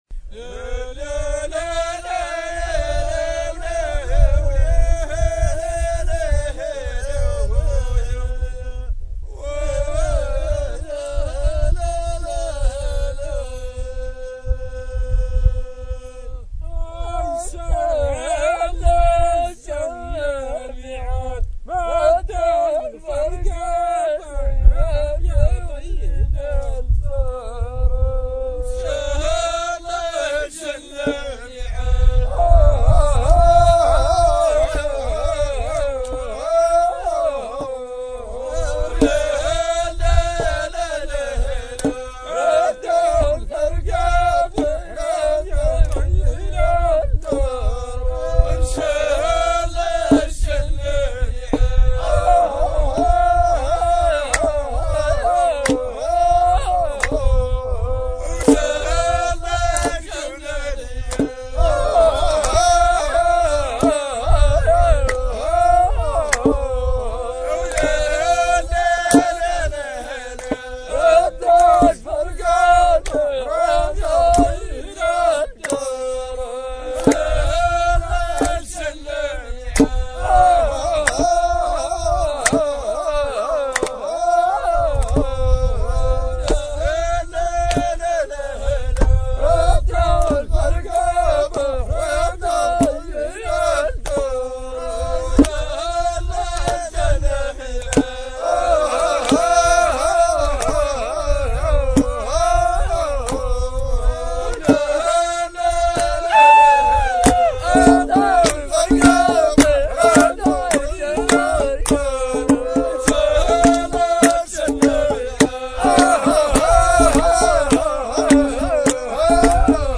Chants traditionnels sahraouis
4 - Rythme " TBAL " ou GUITARE